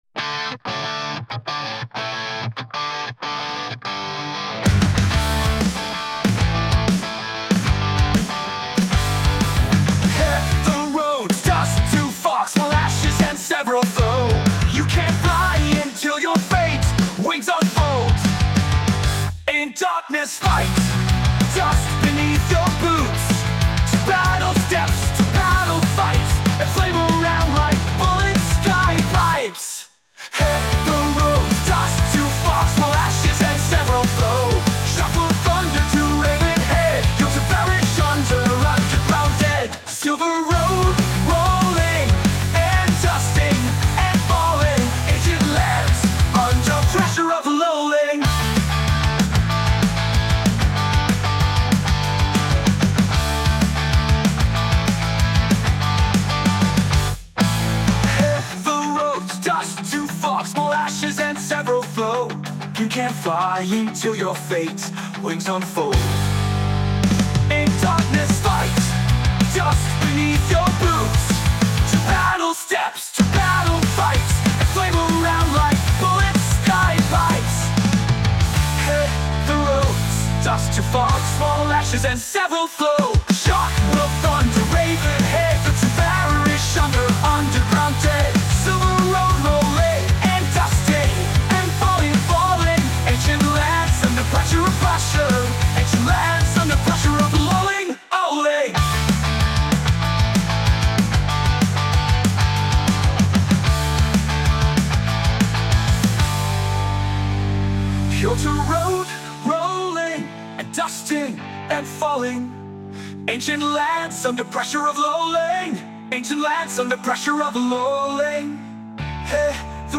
mono-продолжение нейрогена рок поэзии.